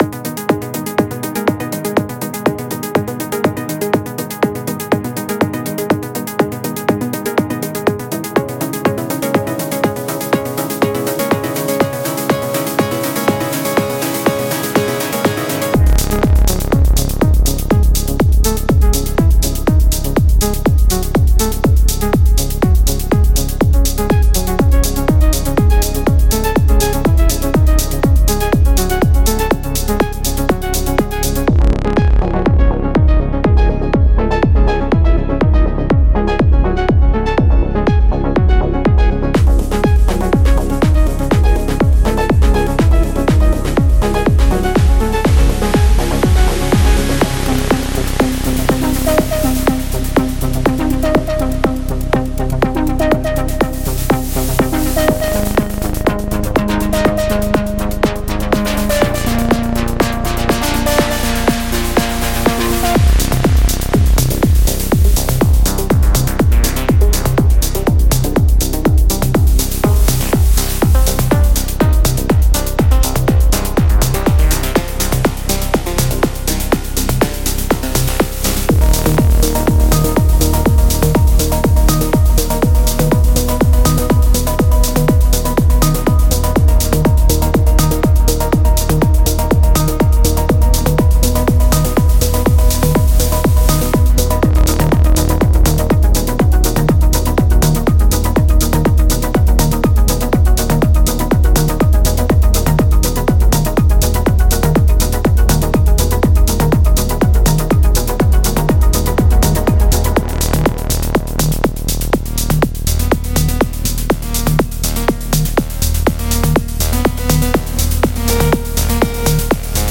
Deep House
Downtempo
Progressive House